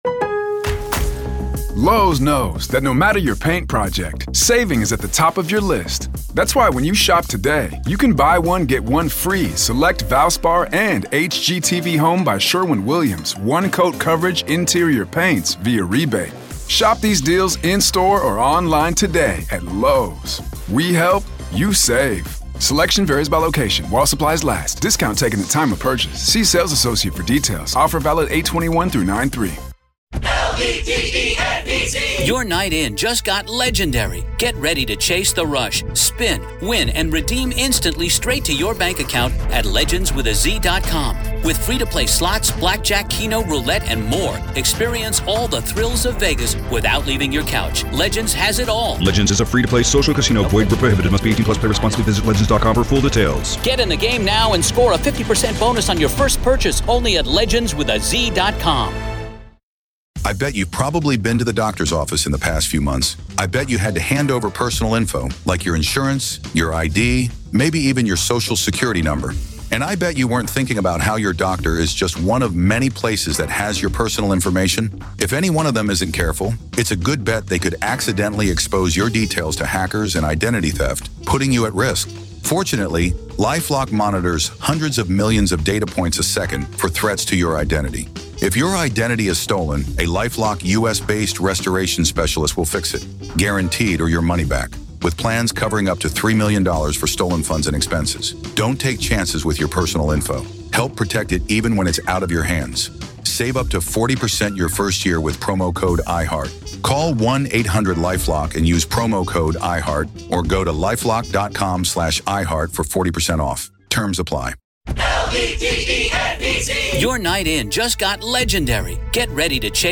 Telles Takes the Stand-Raw Court Audio-NEVADA v. Robert Telles DAY 6 Part 2